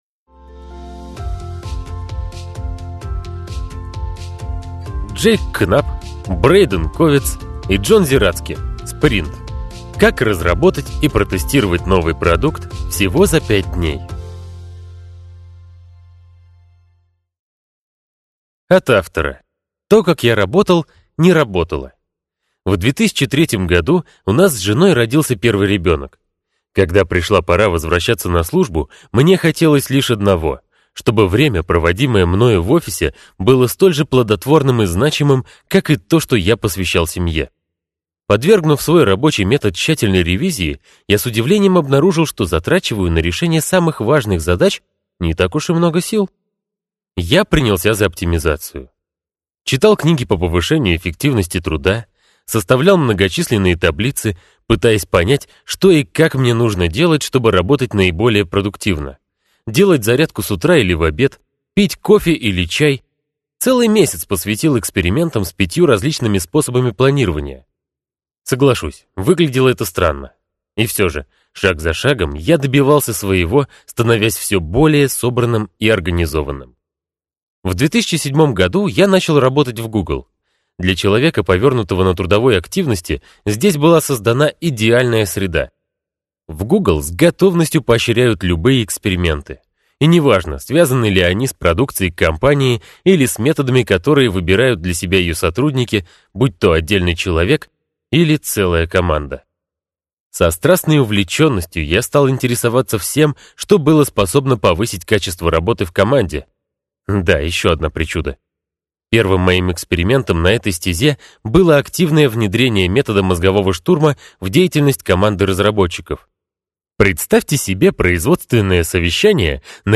Аудиокнига Спринт: Как разработать и протестировать новый продукт всего за пять дней | Библиотека аудиокниг